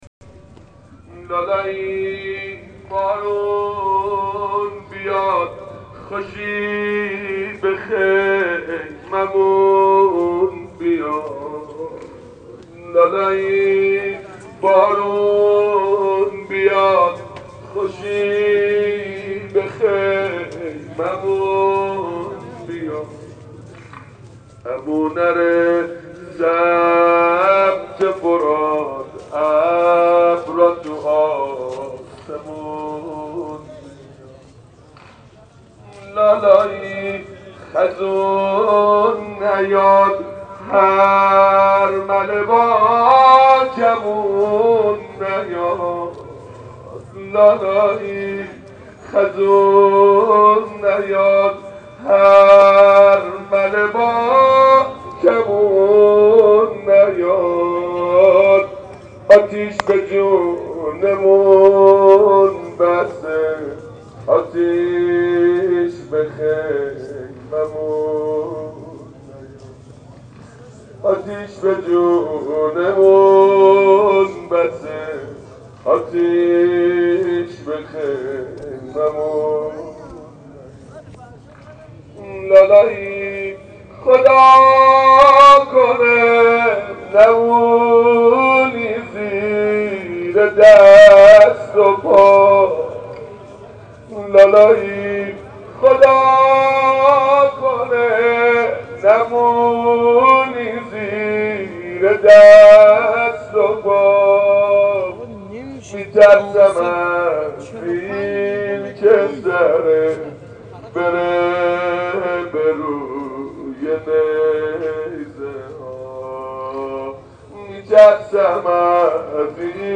عقیق : هفتمین شب از مراسم عزاداری سیدالشهدا در مسجد ارک تهران برگزار شد و هزاران نفر برای شیرخوار کربلا اشک ریختند.
صوت مراسم